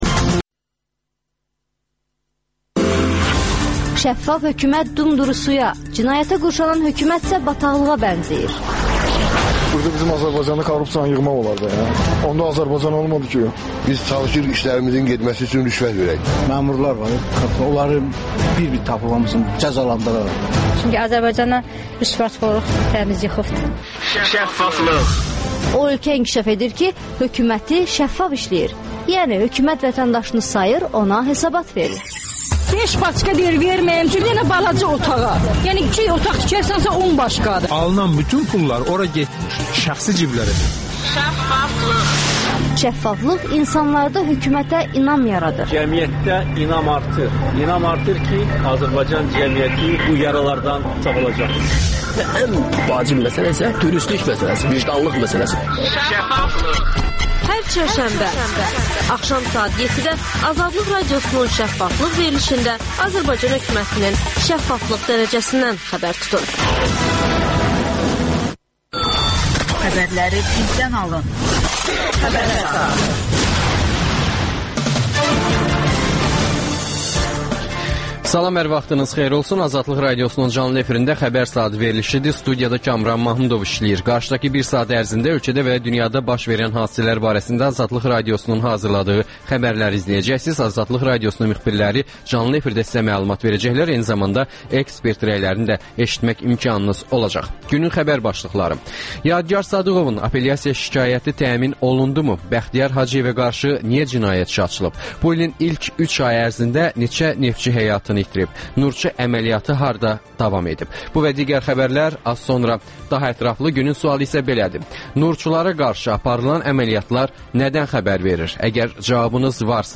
- «Nurçu»lara qarşı əməliyyatlar. AzadlıqRadiosunun müxbirləri ölkə və dünyadakı bu və başqa olaylardan canlı efirdə söz açırlar.